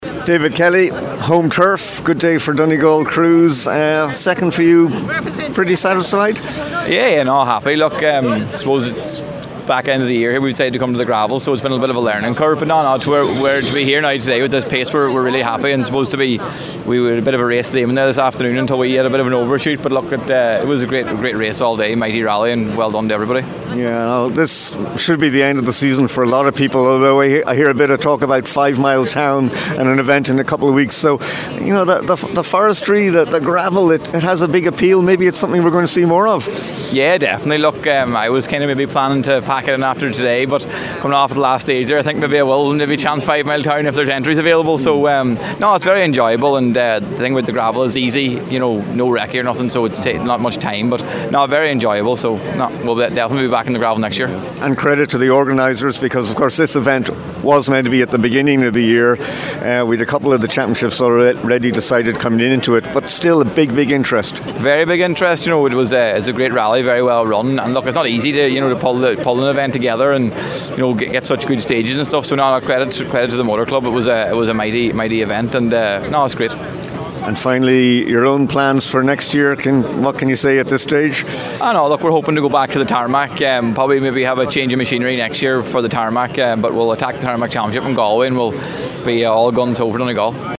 Donegal Forestry Rally – Reaction from the finish line